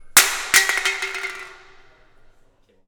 Metal Can Falling Sound Effect
Can Clang Effect Falling Metal Pipe Sound sound effect free sound royalty free Sound Effects